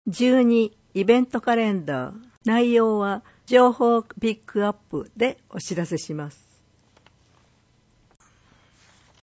広報とうごう音訳版（2019年12月号）